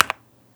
key-press-1.wav